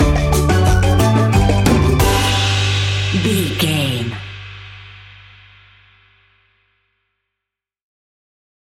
A groovy and upbeat piece of island summer sunshine music.
That perfect carribean calypso sound!
Ionian/Major
steelpan
happy
drums
percussion
bass
brass
guitar